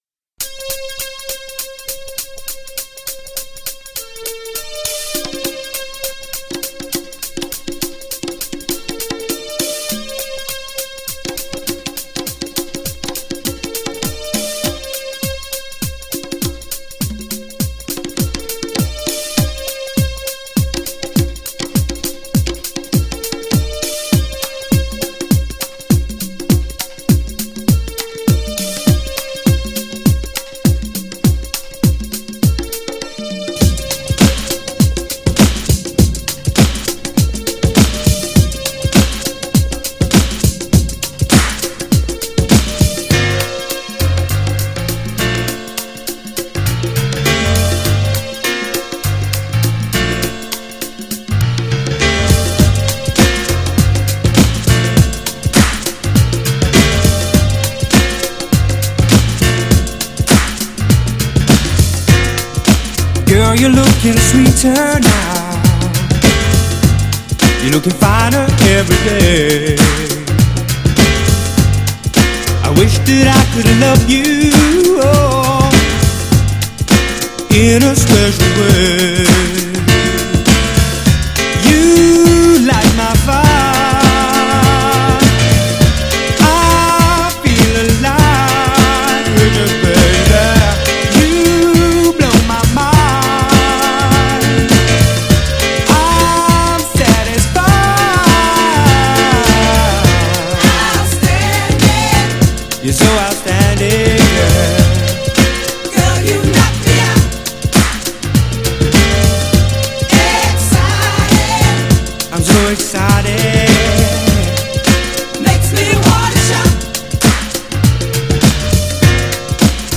GENRE R&B
BPM 101〜105BPM